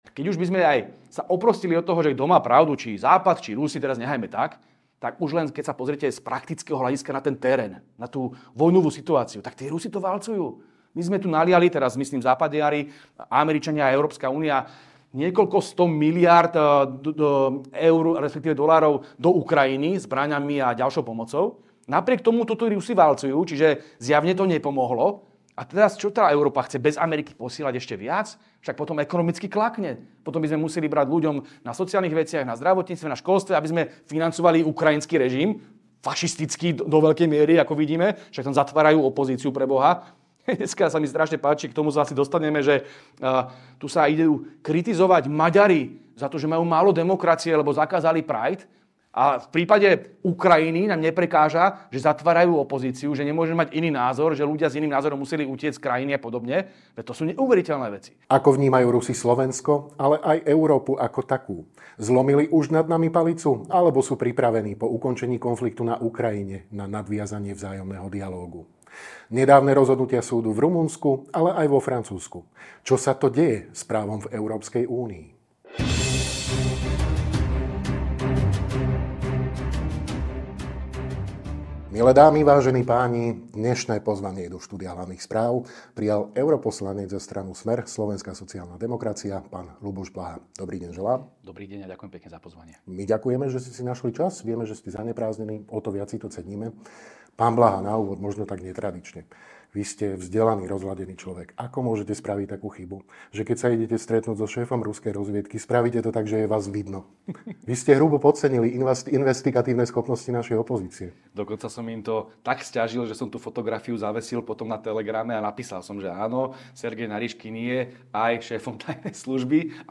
NAŽIVO
Rozprávali sme sa s europoslancom za SMER-SSD, PhDr. Ľubošom Blahom, PhD.